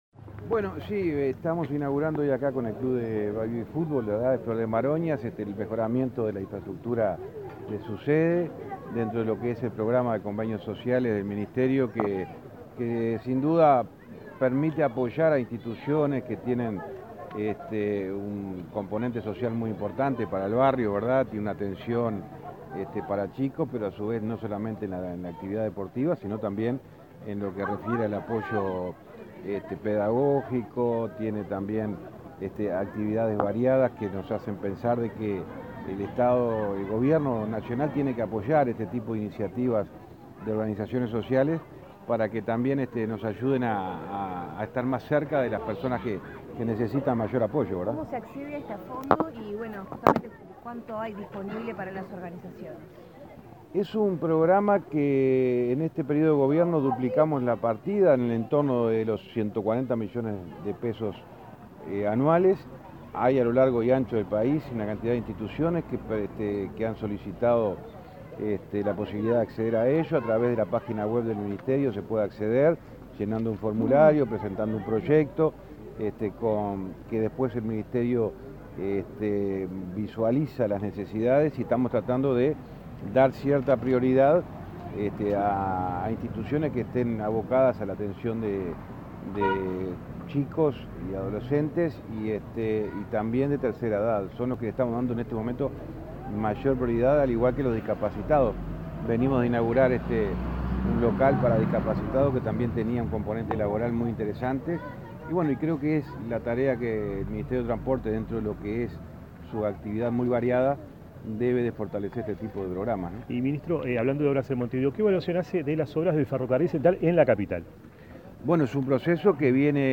Declaraciones a la prensa del ministro de Transporte, José Luis Falero
Declaraciones a la prensa del ministro de Transporte, José Luis Falero 03/06/2022 Compartir Facebook X Copiar enlace WhatsApp LinkedIn El ministro de Transporte y Obras Públicas, José Luis Falero, participará en la inauguración de obras realizadas por organizaciones de Montevideo a través del programa de convenios sociales de la cartera. Luego de visitar el club de baby fútbol Flor de Maroñas dialogó con la prensa.